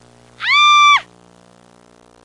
Scream Sound Effect
Download a high-quality scream sound effect.
scream-2.mp3